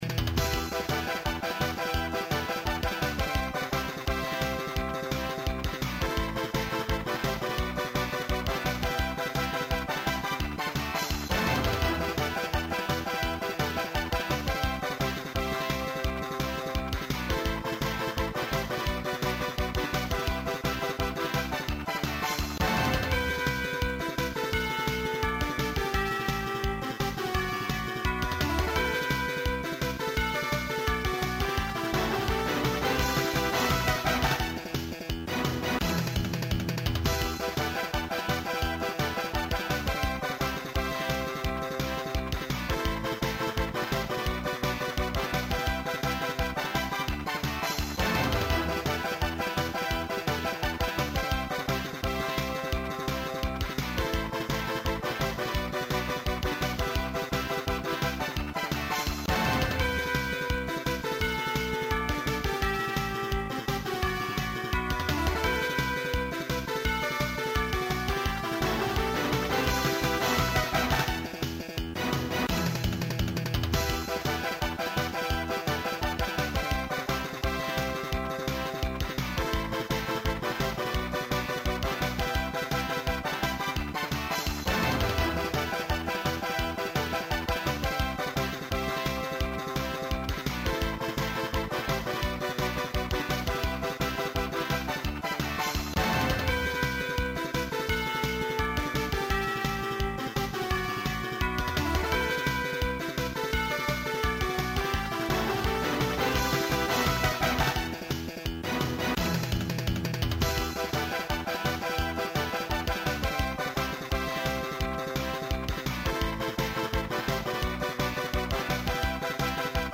Bien kitsch.